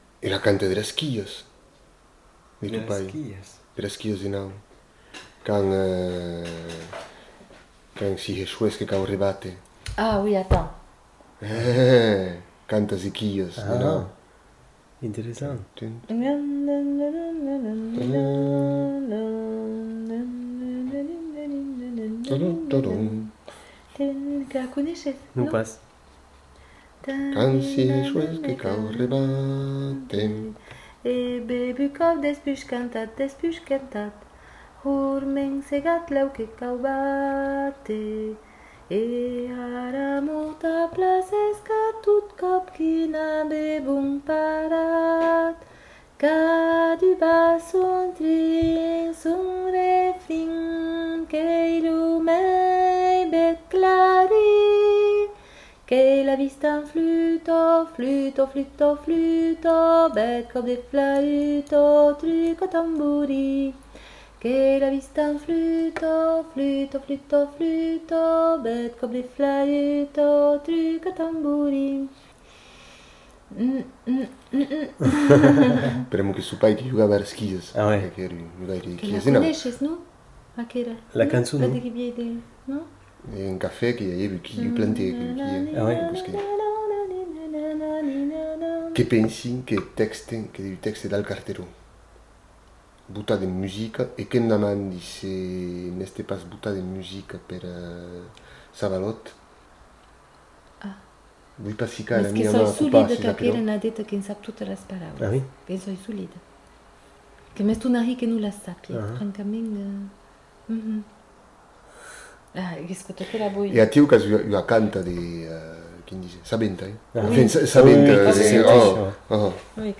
Aire culturelle : Bigorre
Lieu : Ayzac-Ost
Genre : chant
Effectif : 1
Type de voix : voix de femme
Production du son : chanté ; fredonné
Notes consultables : Commentaires en fin de séquence.